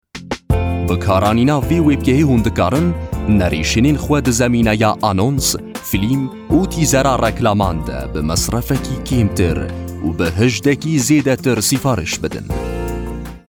Male
Adult
Commercial